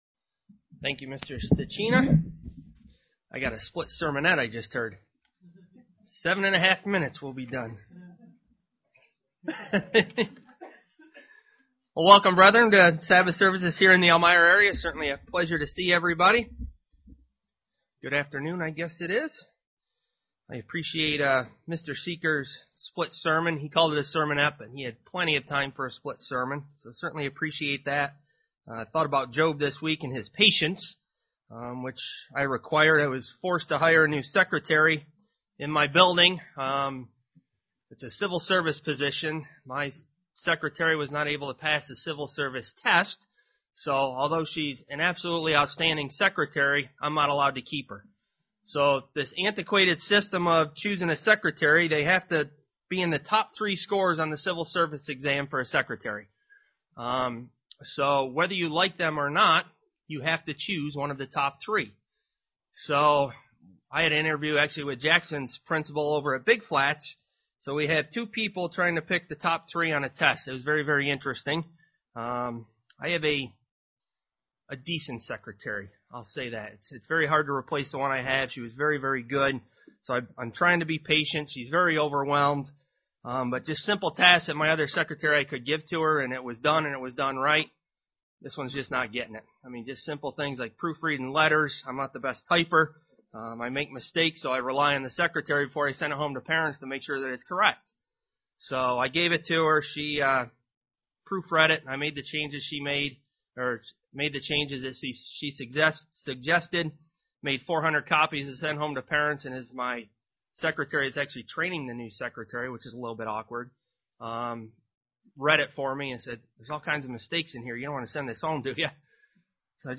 UCG Sermon Studying the bible?
Given in Elmira, NY Buffalo, NY